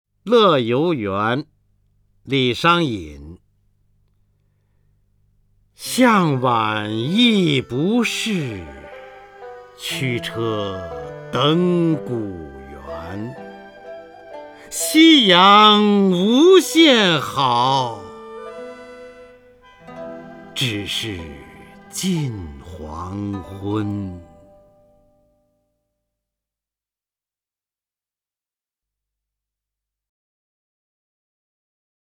方明朗诵：《登乐游原》(（唐）李商隐) （唐）李商隐 名家朗诵欣赏方明 语文PLUS